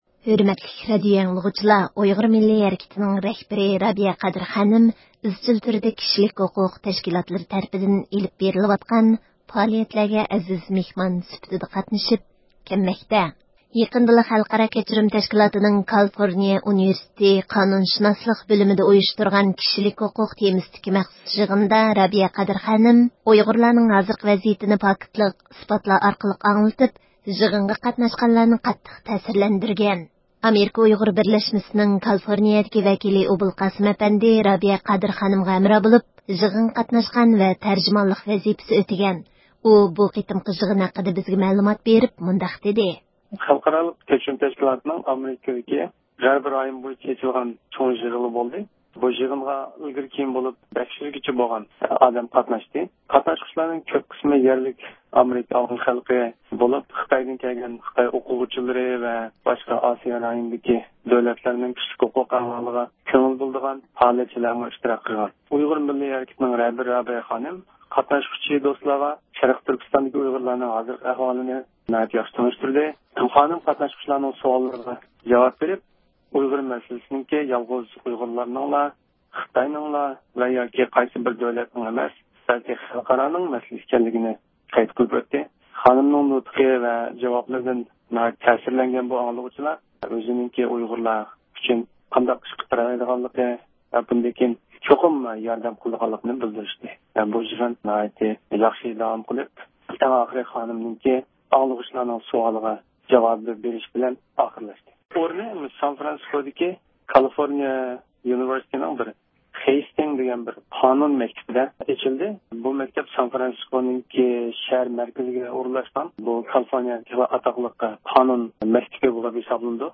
زىيارىتىمىزنى قوبۇل قىلىپ، يىغىندىن ئالغان تەسىراتلىرىنى ئاڭلاتتى.